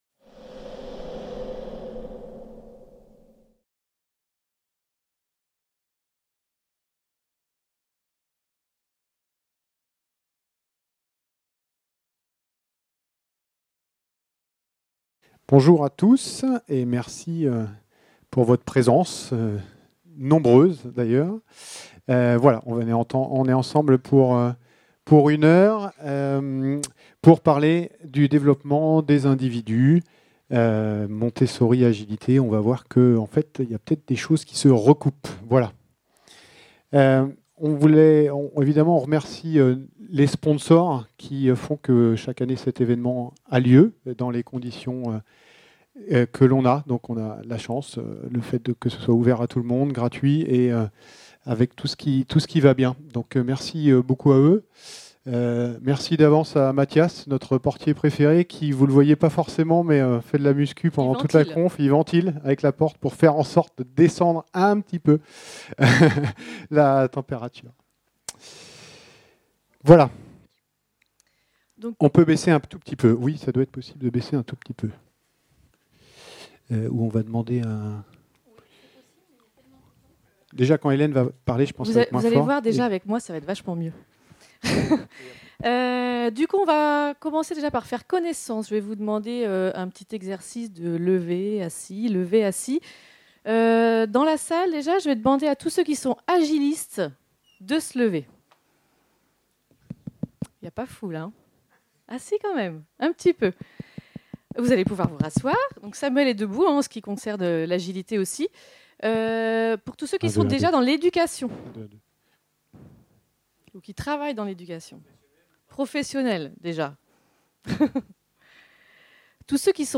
Nous vous proposons d’explorer les points communs entre ces approches et partager nos meilleures pratiques car finalement ce sont les mêmes (ou presque). Type : Conférence